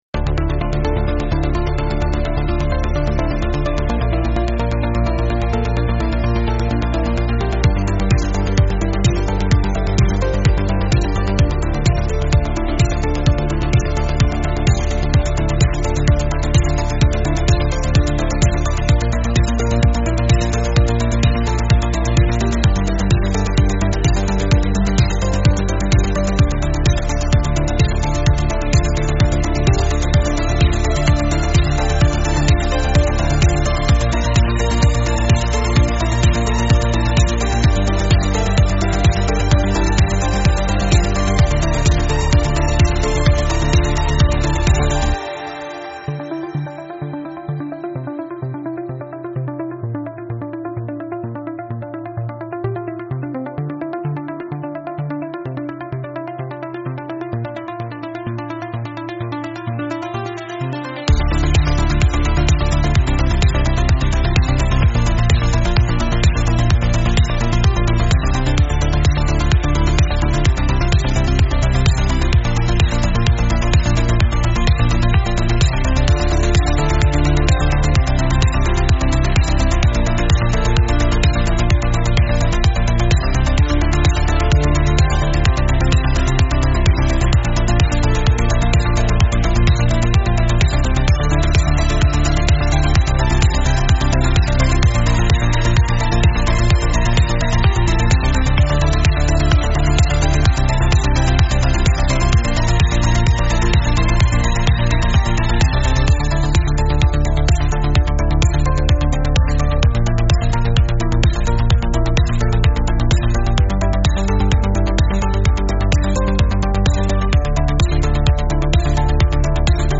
Instrumental - Real Liberty Media DOT xyz - Grimnir Radio